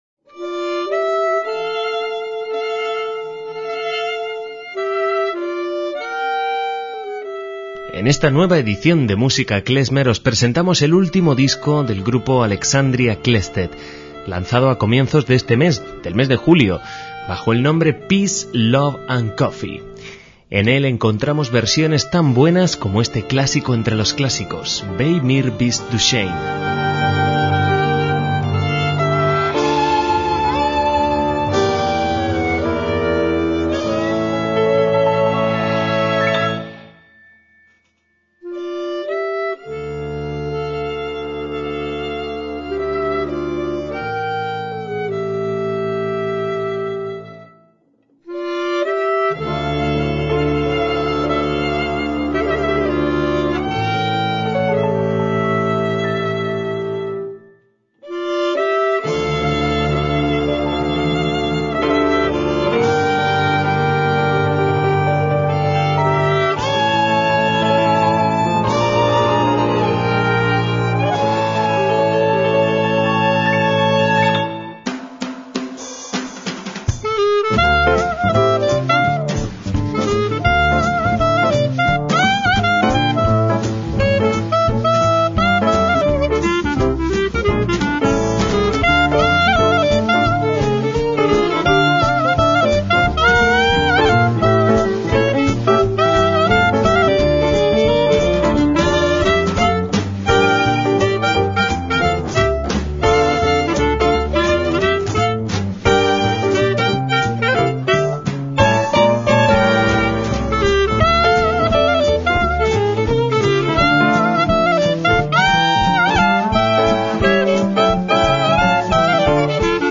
MÚSICA KLEZMER
percusiones
bajo y contrabajo
violín y mandolina